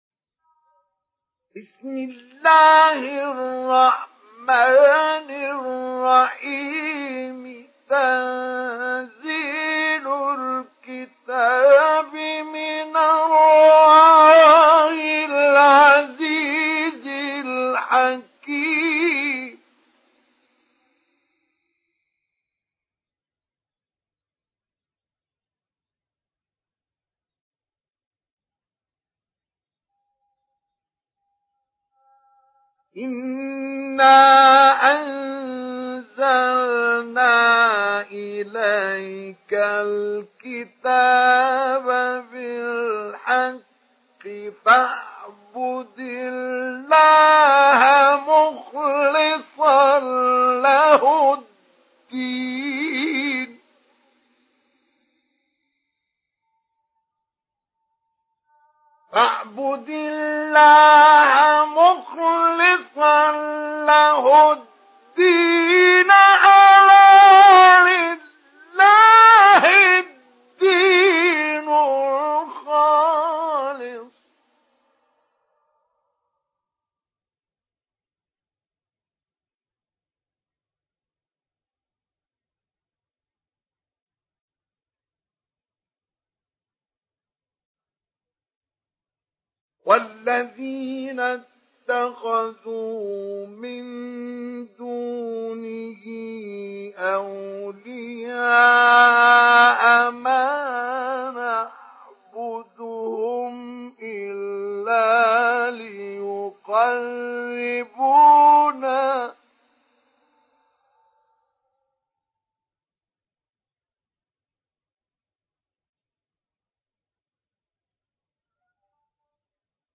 İndir Zümer Suresi Mustafa Ismail Mujawwad